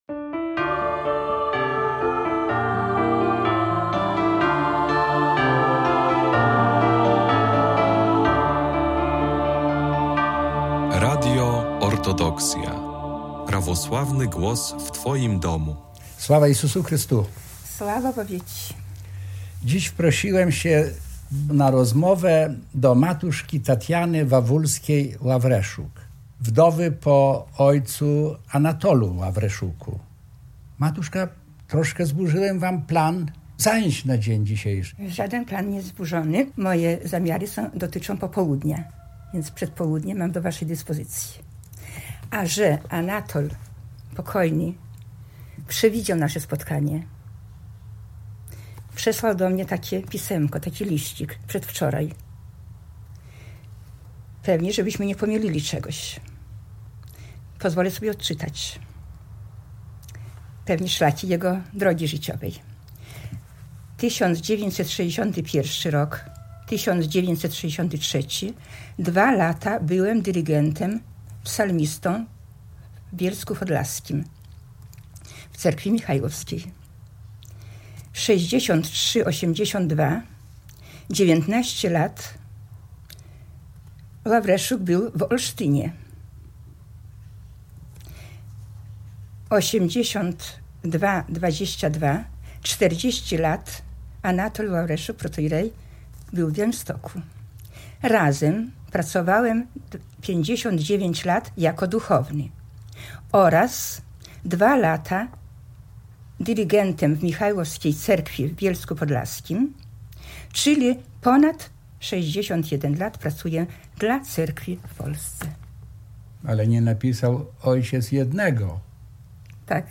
A kiedyś to było… rozmowy o życiu i Cerkwi – to cykl audycji radiowych z osobami, którzy tworzyli historię Polskiego Autokefalicznego Kościoła Prawosławnego. W każdym odcinku programu zaproszeni goście opowiadają o swoich wspomnieniach związanych z pracą na rzecz Cerkwi na przestrzeni ostatnich kilkudziesięciu lat.